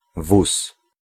Ääntäminen
IPA : /kɑːt/ GenAm: IPA : /kɑɹt/